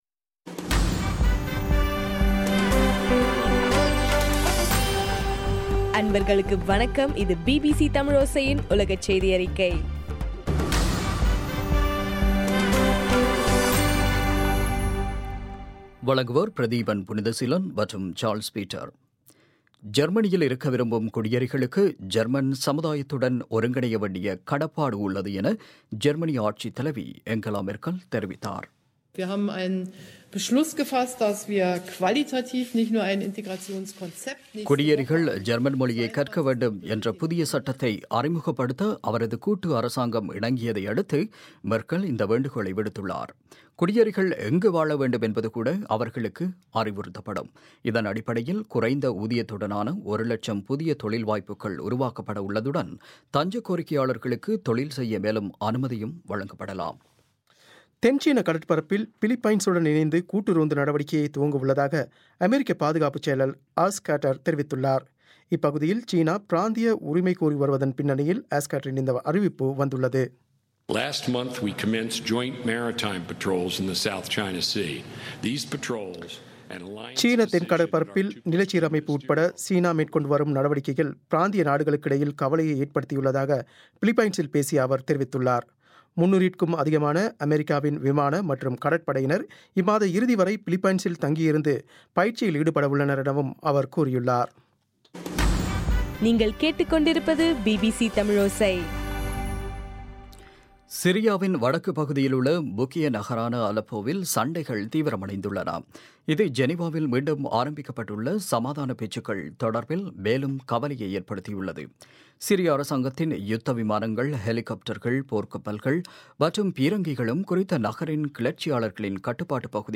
14 ஏப்ரல் 2016, பிபிசி செய்தியறிக்கை